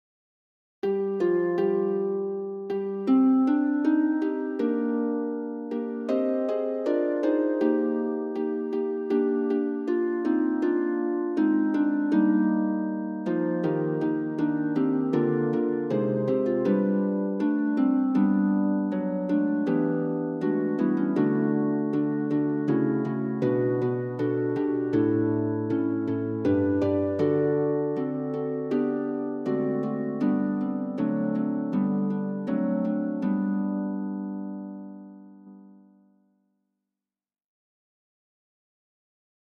SSATB (5 gemischter Chor Stimmen) ; Partitur.
Genre-Stil-Form: geistlich ; Tropar
Instrumentation: Klavier (ad lib)
Tonart(en): Es-Dur